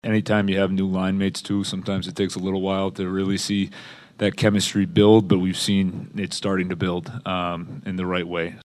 Muse is impressed with the skills Egor Chinakhov brings to the Penguins, and says the recent acquisition from the Blue jackets is developing a rapport with Evgeni Malkin.